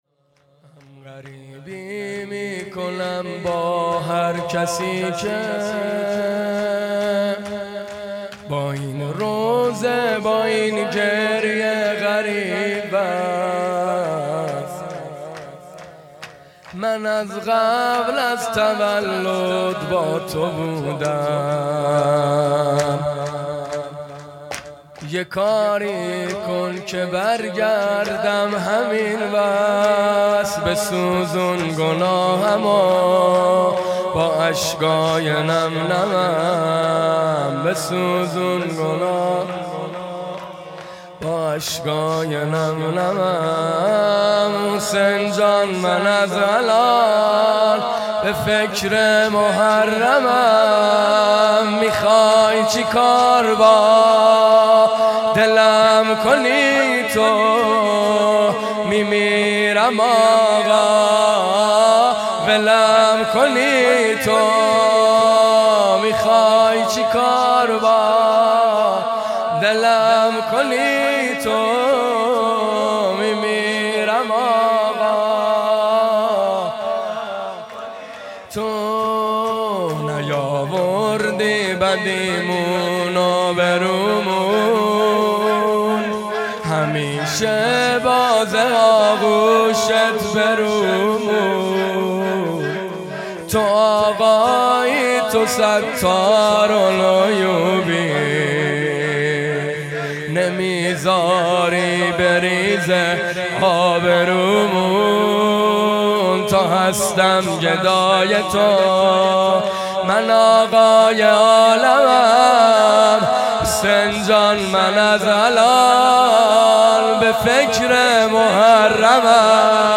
شور
بدرقه جهادگران